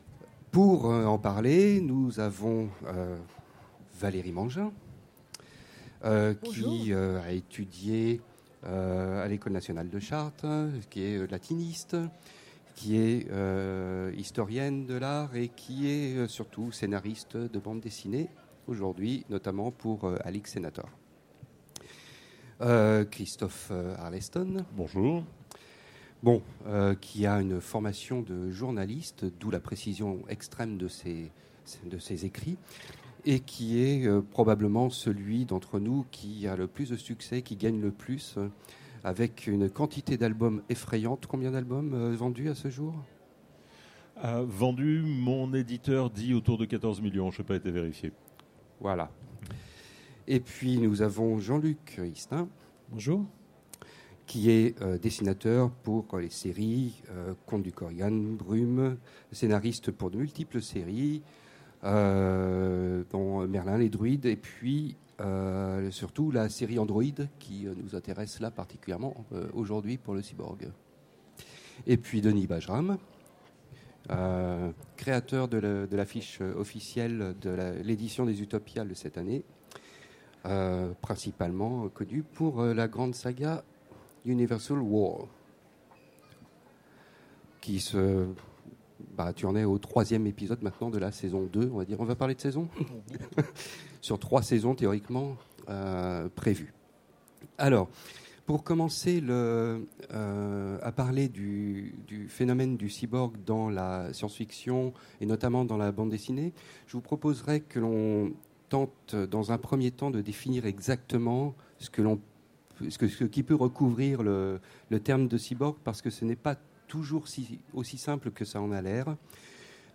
Utopiales 2016 : Conférence Fantasmes du cyborg en BD